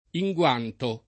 inguanto [ i jgU# nto ]